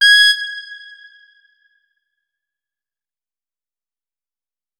saxophone
notes-68.ogg